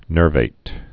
(nûrvāt)